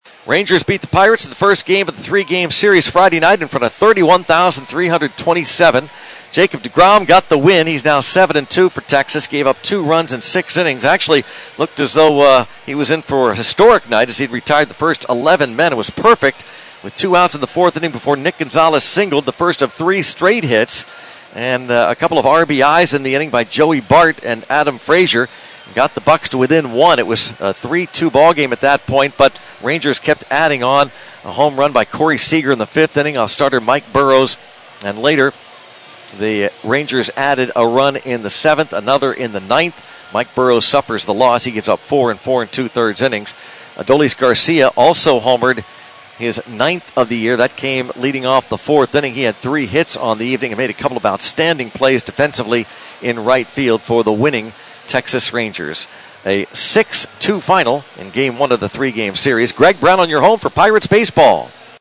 reports on last night’s loss to the Texas Rangers.